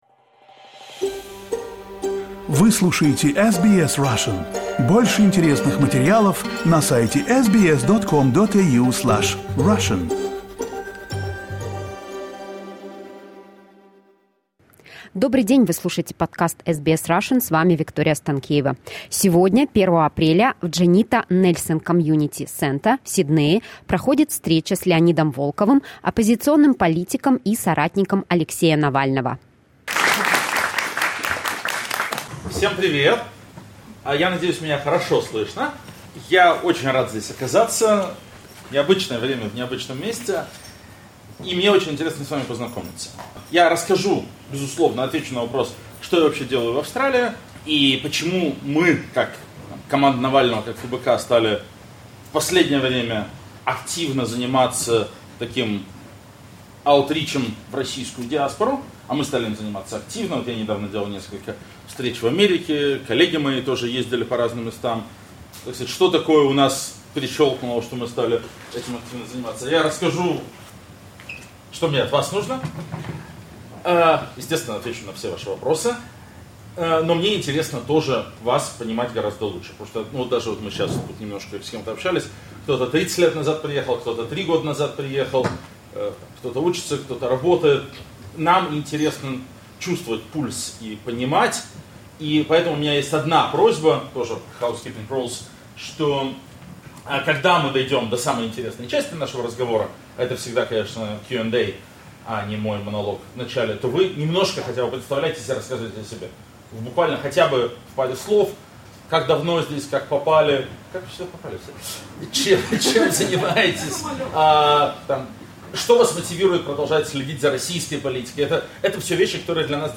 On Saturday, April 1, the Juanita Nielsen Community Center hosted a meeting of the Russian-speaking community with Leonid Volkov, an opposition politician and colleague of Alexei Navalny.